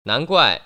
[nánguài] 난꽈이